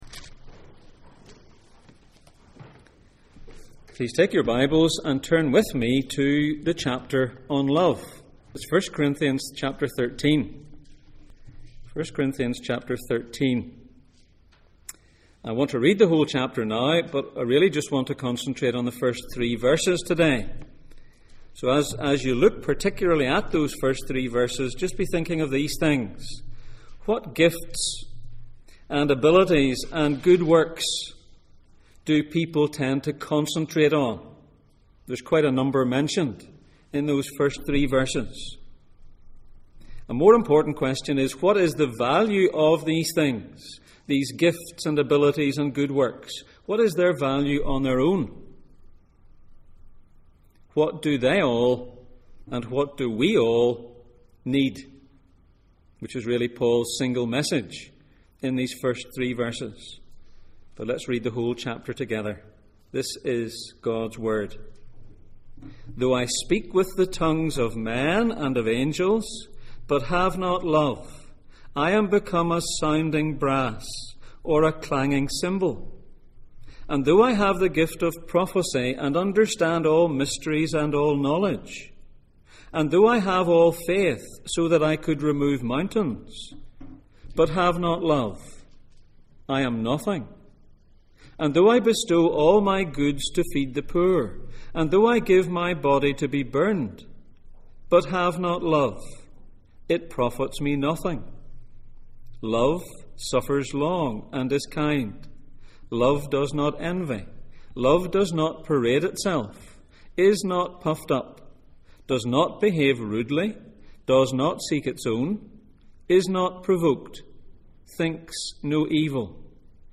In praise of love Passage: 1 Corinthians 13:1-13, 1 Corinthians 8:1, John 11:49-52 Service Type: Sunday Morning